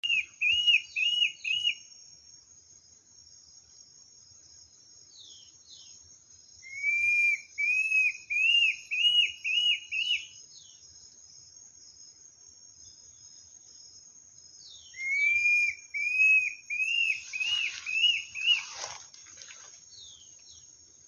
Borralhara (Mackenziaena severa)
Nome em Inglês: Tufted Antshrike
Localidade ou área protegida: Santa Ana
Condição: Selvagem
Certeza: Gravado Vocal
batara-copeton.mp3